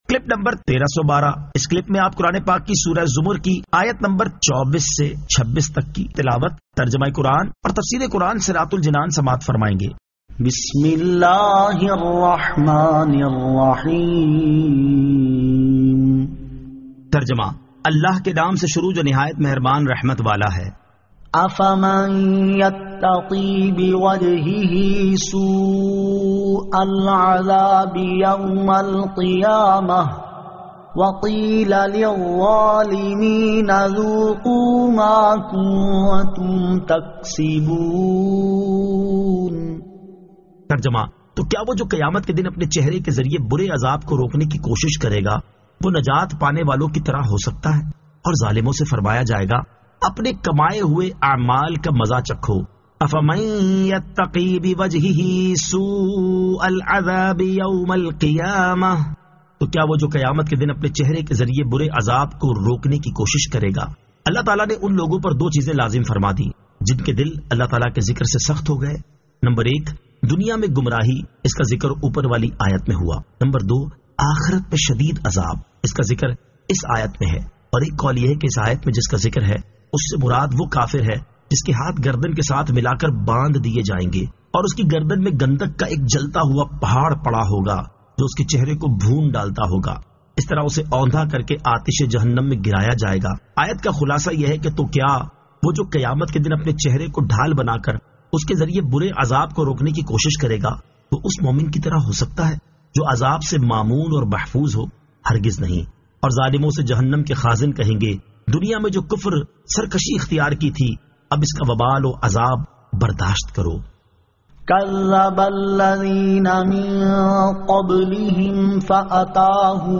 Surah Az-Zamar 24 To 26 Tilawat , Tarjama , Tafseer